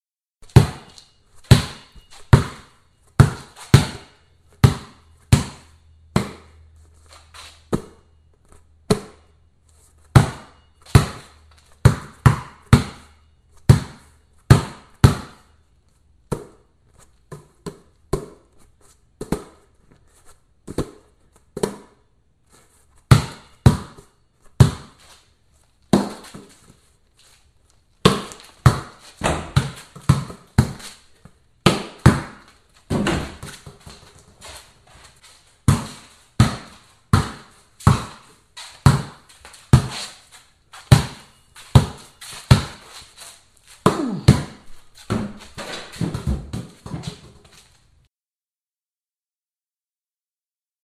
На этой странице собраны звуки мяча в разных ситуациях: удары, отскоки, броски.
Шум мяча во время баскетбольного дриблинга